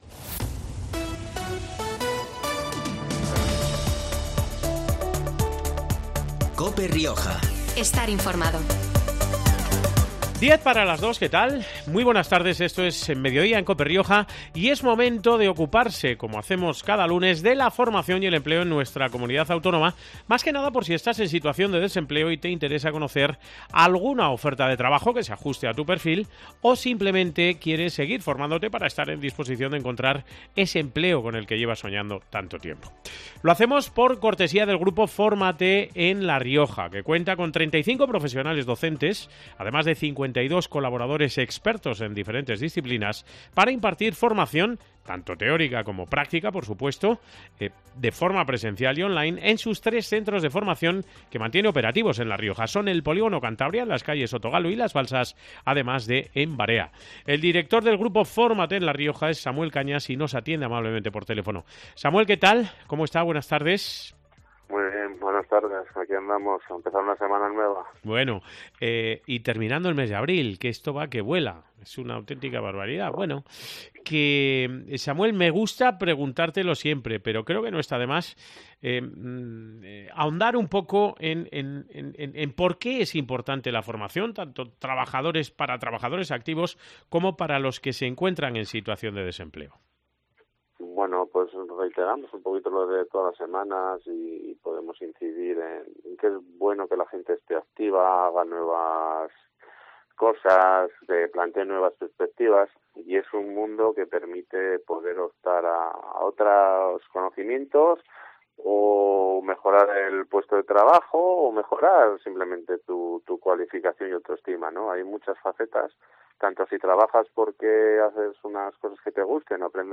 El grupo 'Fórmate en La Rioja' patrocina la sección de ofertas de empleo y formación en COPE Rioja, que esta semana incluye dos propuestas laborales y cuatro cursos formativos